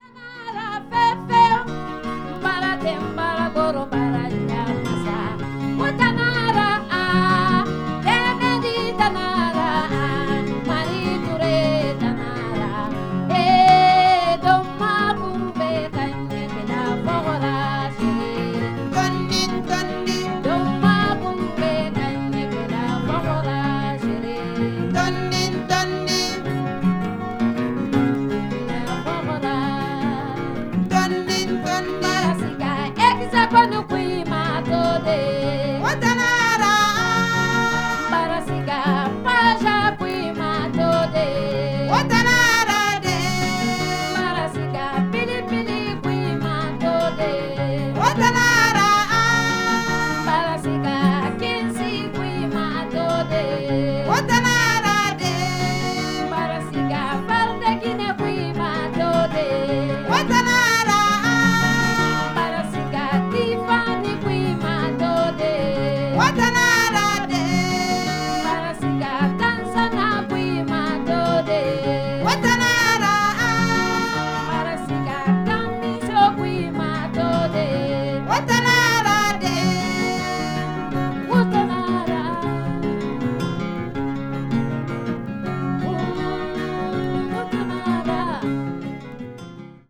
フランス・パリでの録音作品。
穏やかな気持ちになります。2トラックのビデオ・テープにダイレクト・レコーディングされた音源とのことで、素晴らしい音質。